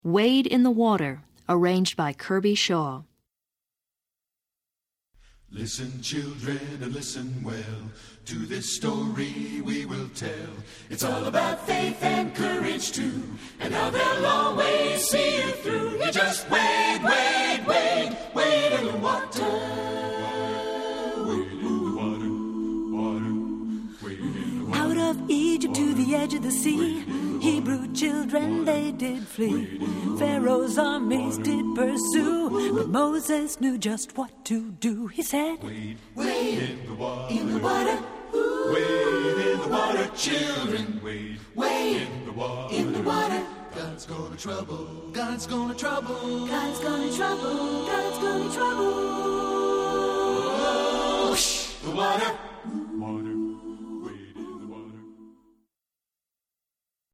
Composer: Spiritual
Voicing: SATB a cappella